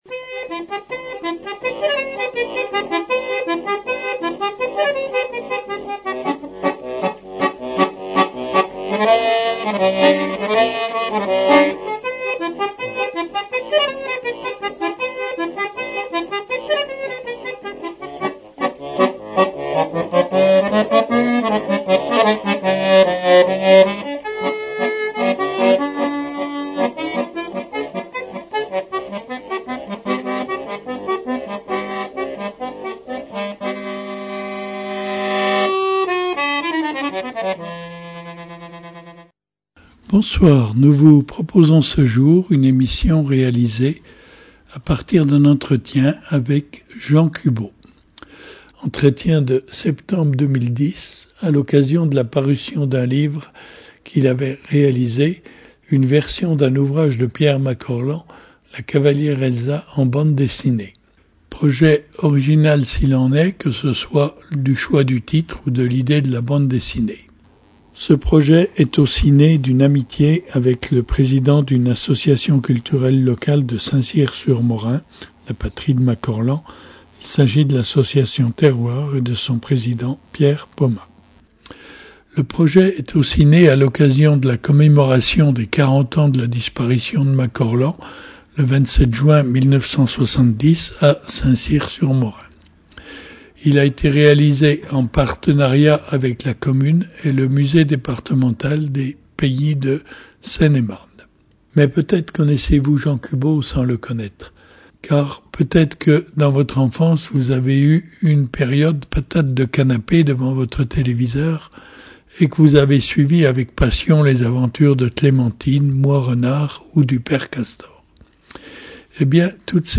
est consacrée à un entretien
Entretien enregistré en septembre 2010